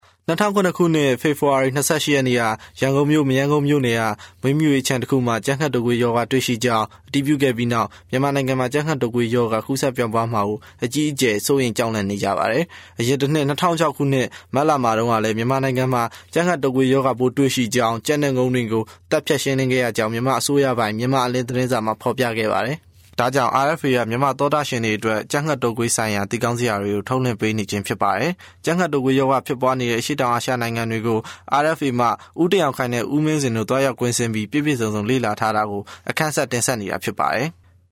Professioneller burmesischer Sprecher für Werbung, TV, Radio, Industriefilme und Podcasts Professional male burmese voice over artist
Kein Dialekt
Professional male burmese voice over artist